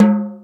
Hip House(53).wav